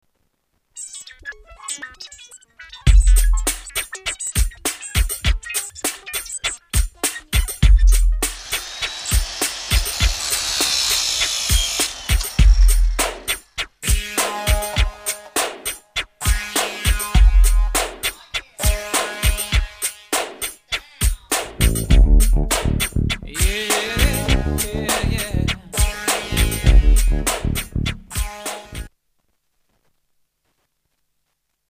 STYLE: Rock
complete with electric saw effects